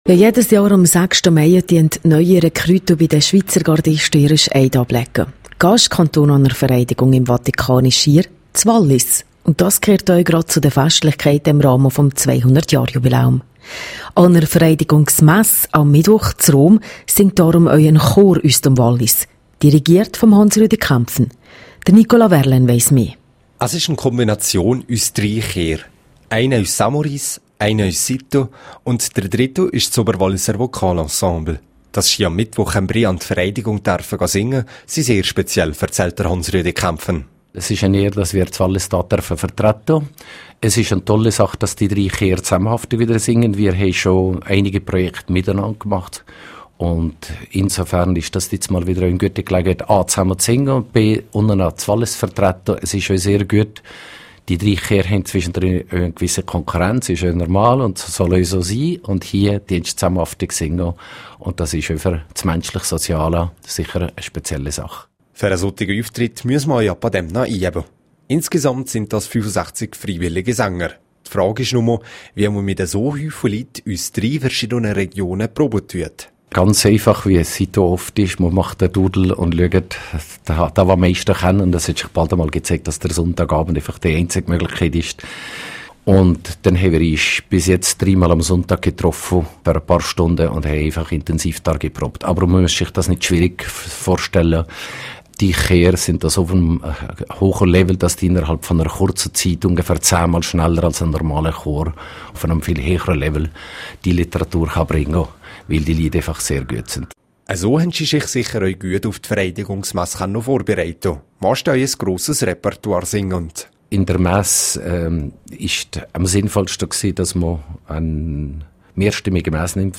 10896_News.mp3